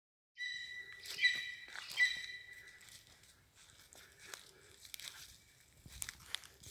Birds -> Birds of prey ->
Lesser Spotted Eagle, Clanga pomarina